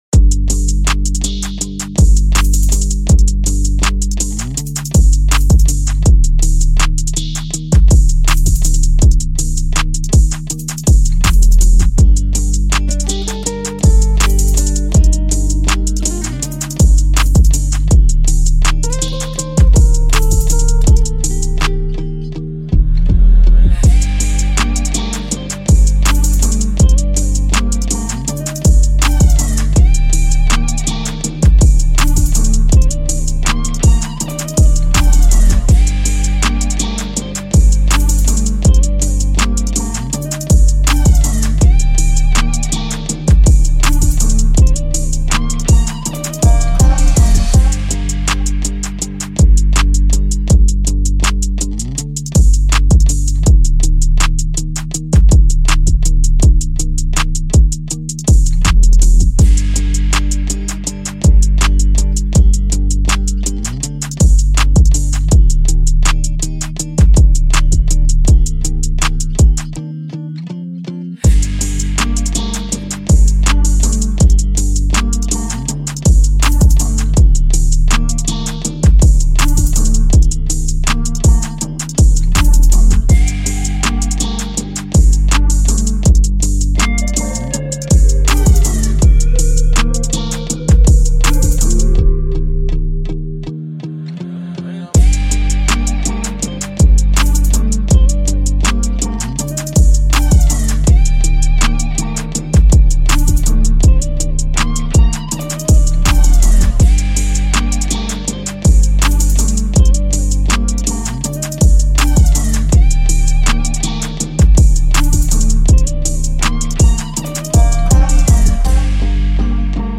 Here's the official instrumental of